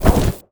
fireball_projectile_deflect_02.wav